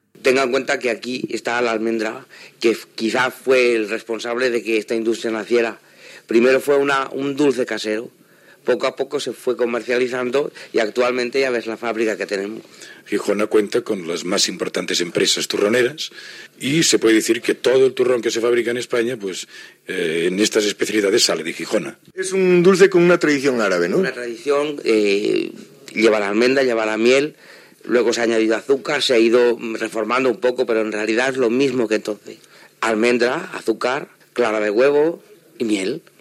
Documental